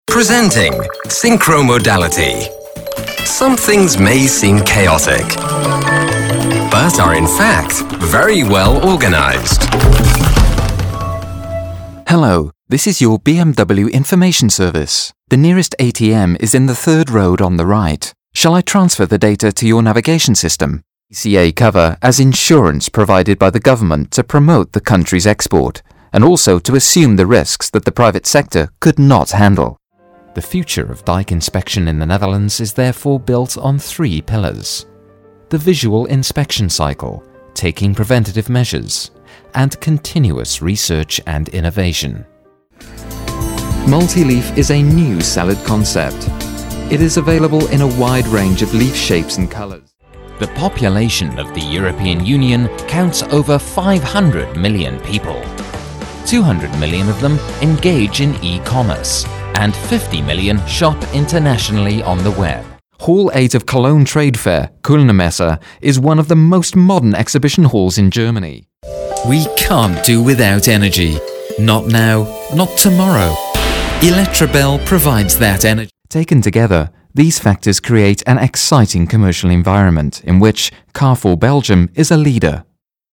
Masculino
Inglês - Reino Unido
General demo